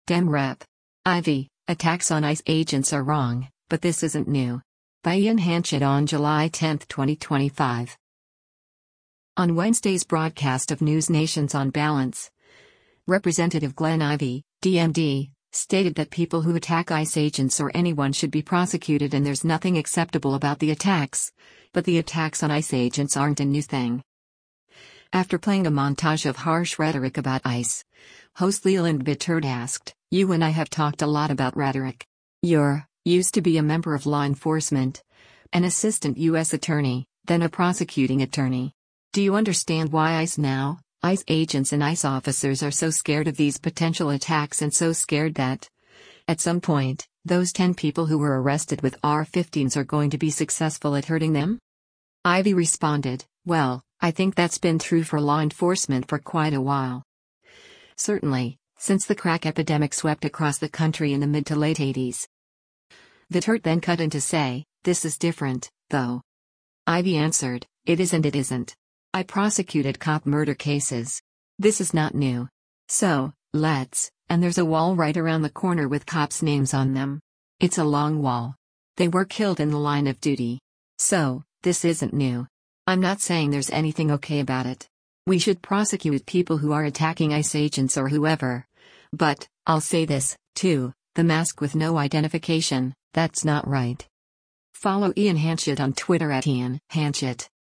On Wednesday’s broadcast of NewsNation’s “On Balance,” Rep. Glenn Ivey (D-MD) stated that people who attack ICE agents or anyone should be prosecuted and there’s nothing acceptable about the attacks, but the attacks on ICE agents aren’t a new thing.